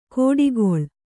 ♪ kōḍigoḷ